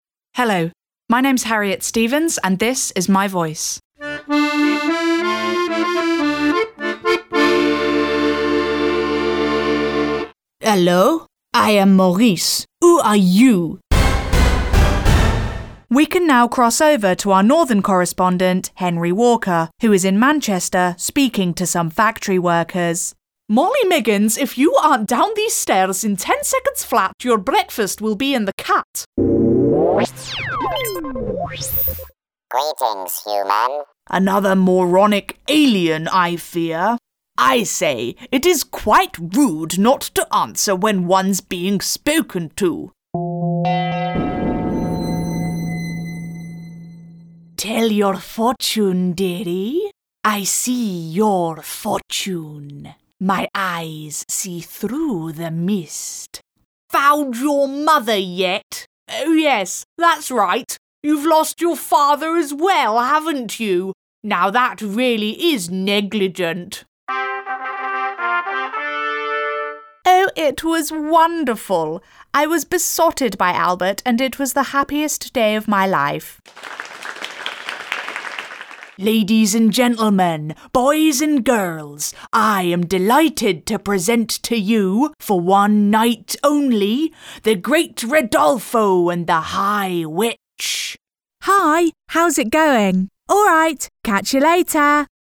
voiceover, English, British, young, female, character, advertising, BBC, energetic, corporate, sales, explainer videos, animation voices, audio books, kids,
britisch
Sprechprobe: Sonstiges (Muttersprache):